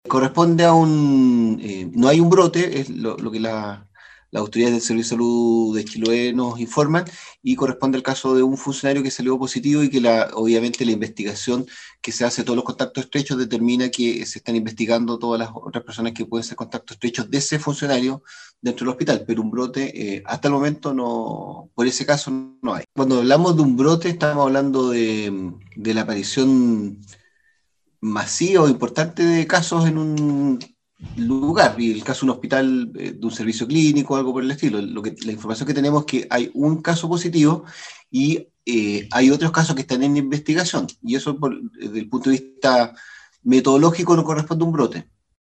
Sobre lo ocurrido en el Hospital de Ancud, el director del Servicio de Salud del Reloncaví Jorge Tagle, quien es a su vez, coordinador de camas críticas de la red de salud de Los Lagos, indicó que esta situación no reunía las condiciones para ser denominado como un “brote”